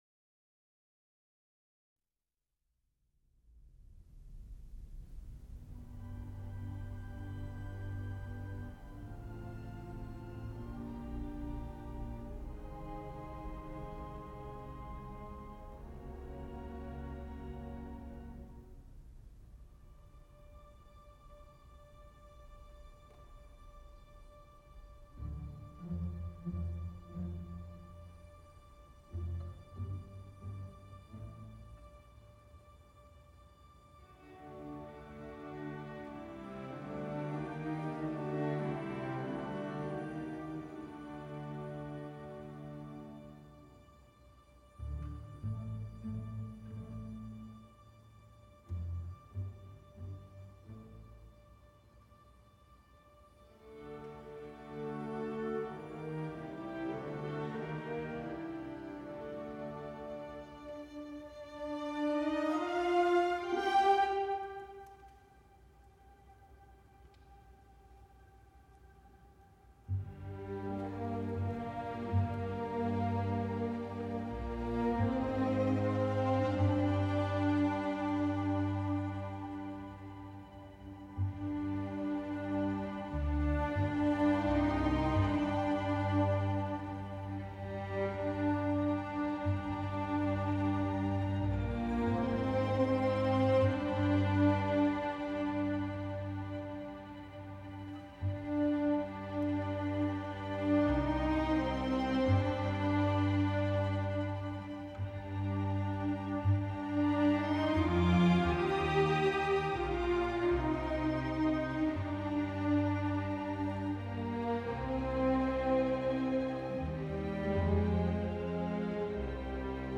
Ralph Vaughan Williams, beautiful music but possibly not a good choice, a little melancholy.